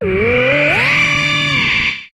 Cri de Dragmara dans Pokémon HOME.